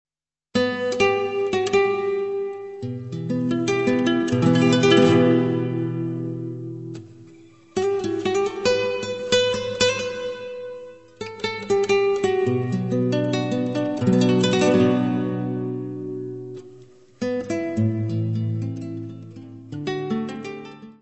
Área:  Tradições Nacionais